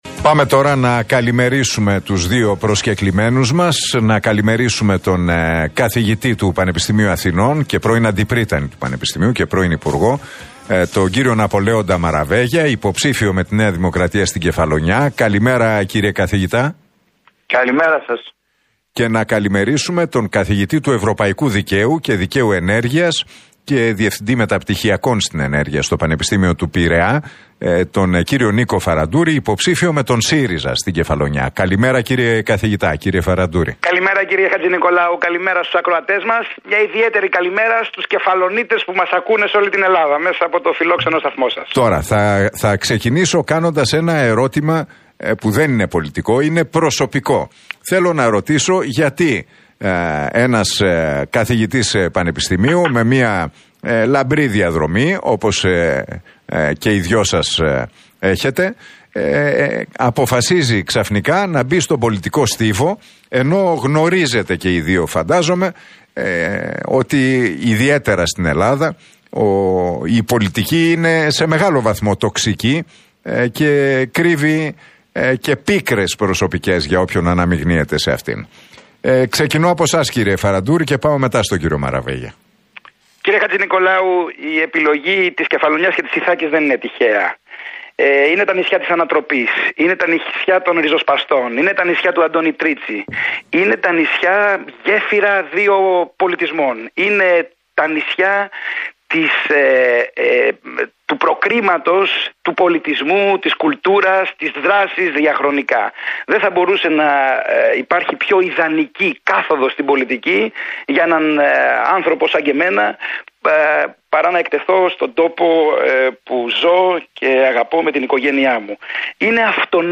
Τα ξίφη τους διασταύρωσαν στον αέρα του Realfm 97,8 σε ένα ραδιοφωνικό debate, στην εκπομπή του Νίκου Χατζηνικολάου οι υποψήφιοι βουλευτές στην Κεφαλονιά με